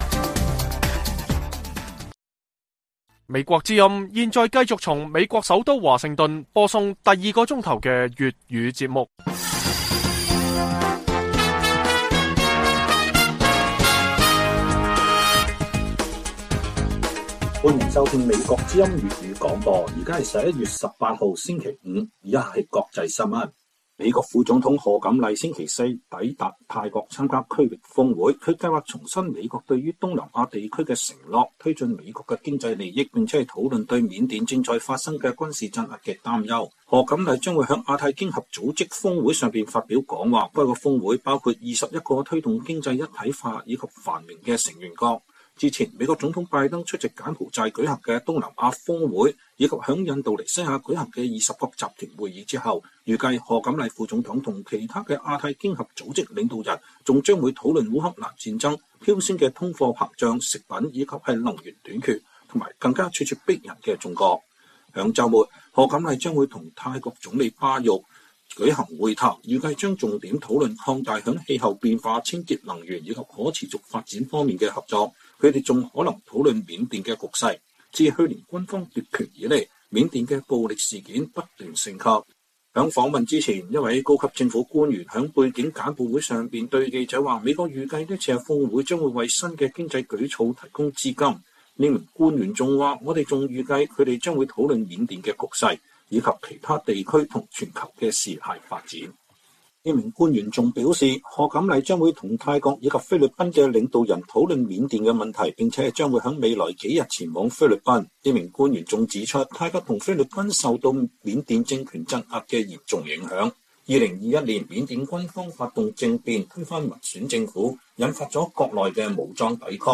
粵語新聞 晚上10-11點: 美國副總統賀錦麗抵達泰國曼谷參加亞太經合組織峰會